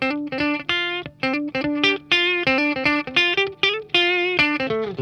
RARE RIFF HI.wav